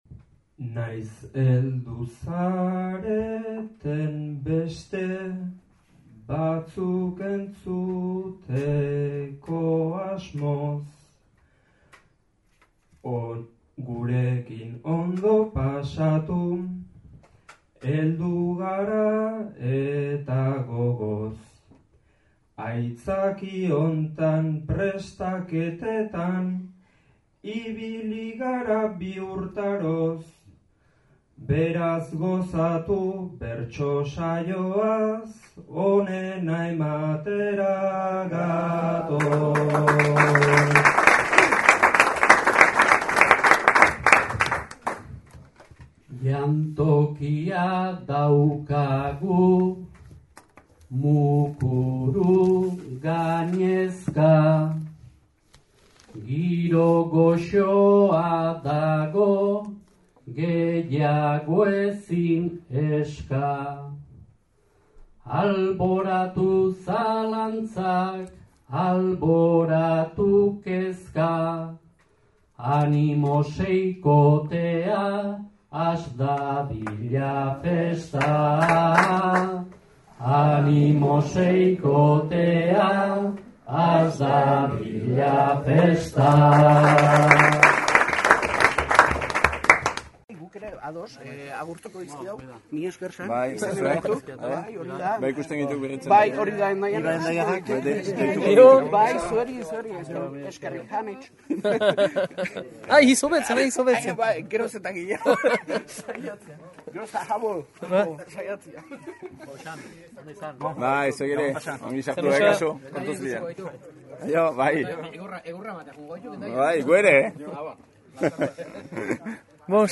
erreportaje xilaba krakada.mp3